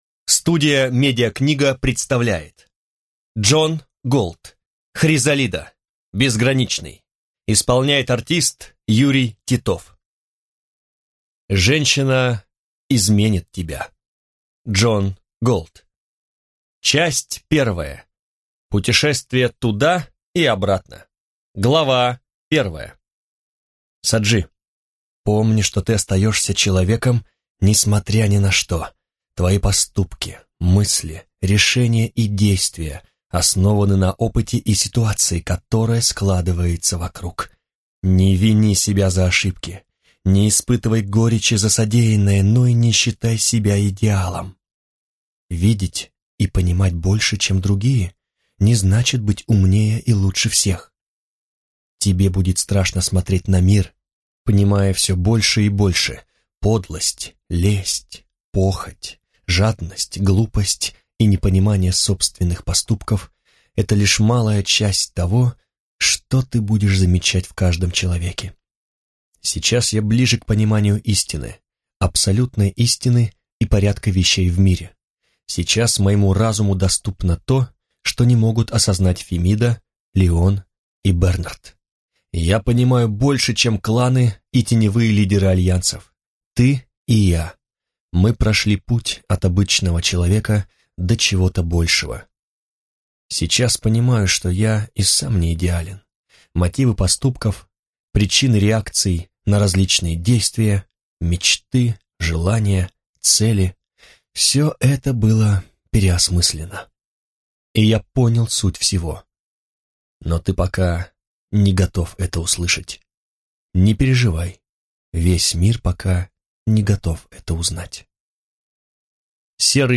Аудиокнига Хризалида. Безграничный | Библиотека аудиокниг